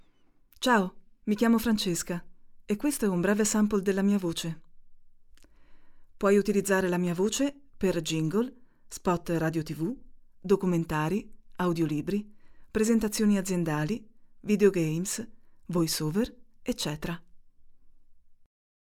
Female
Confident, Corporate, Deep, Natural, Posh, Reassuring, Warm, Versatile
North Italy (native)
Microphone: RODE NT1